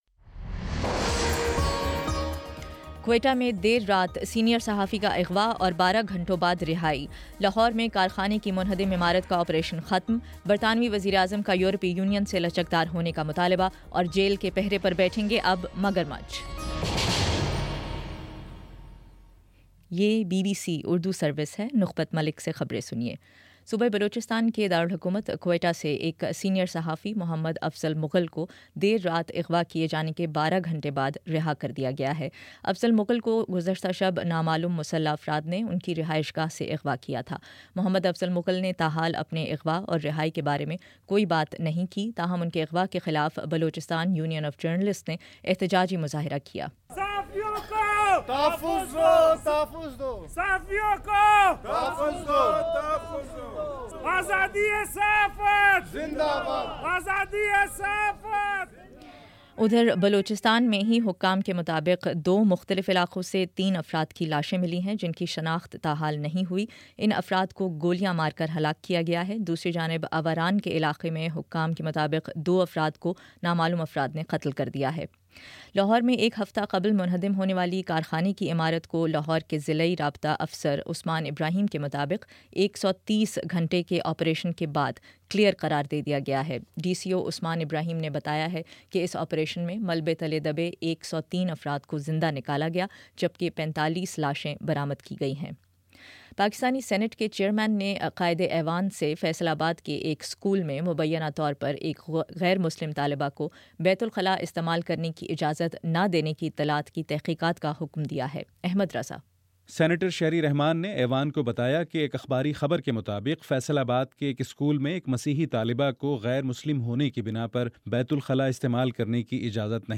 نومبر 10 : شام پانچ بجے کا نیوز بُلیٹن